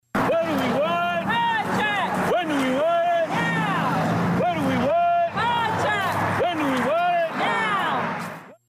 Teachers and support personnel in the Westville Unit 2 School District staged informational picketing last evening (Wednesday) in hopes of getting contract talks resolved….